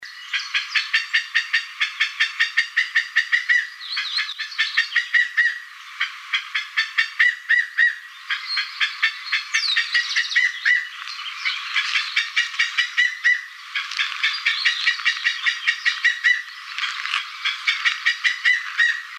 Chiricote (Aramides cajaneus)
Nombre en inglés: Grey-cowled Wood Rail
Localidad o área protegida: Reserva Ecológica Costanera Sur (RECS)
Condición: Silvestre
Certeza: Fotografiada, Vocalización Grabada